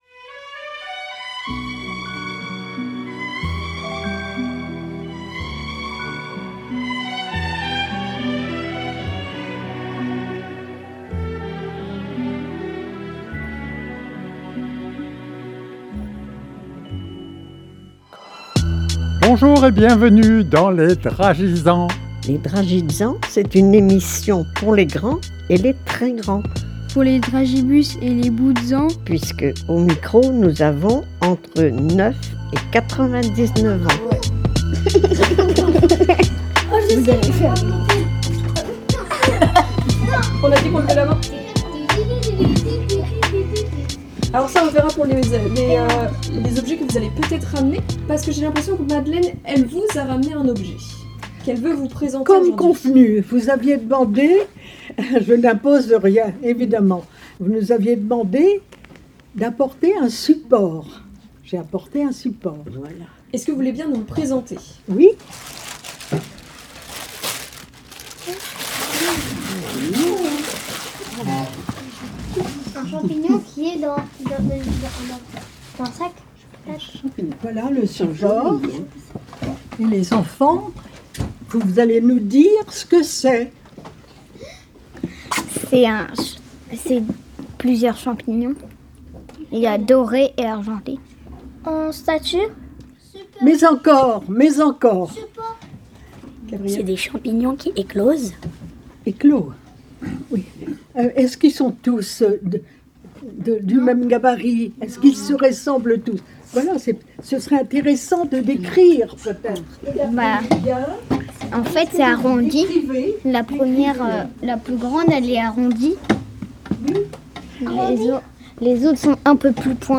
Et oui, dans cette émission, les participants et participantes ont entre 9 et 99 ans.